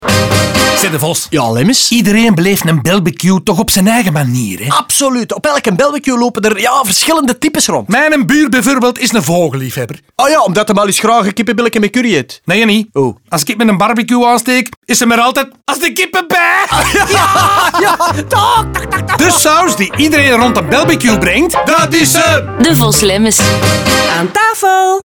Maar geen Devos & Lemmens campagne zonder een rijk assortiment radiospots natuurlijk. Ook daar vormen de verschillende types een rode draad doorheen de spots.